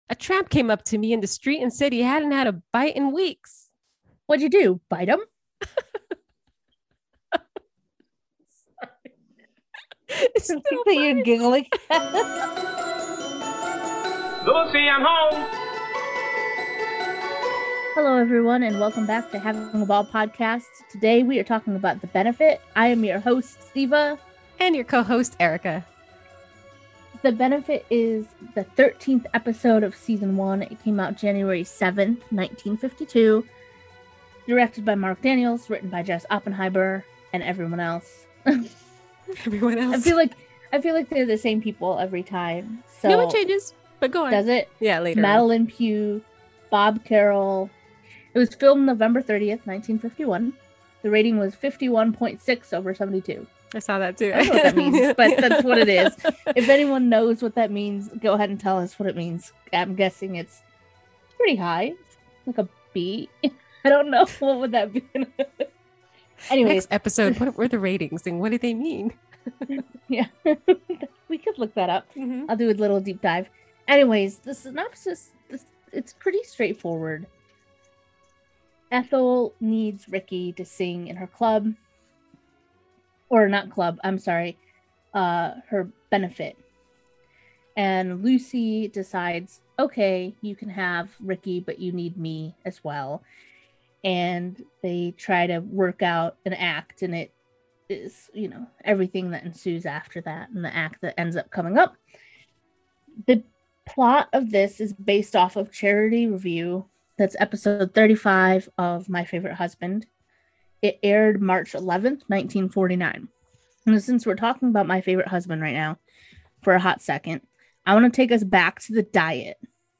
in this episode, the hosts discuss the hilarious episode that is The Benefit. We discuss how Lucy was treated in this episode and how we would behave in our everyday lives.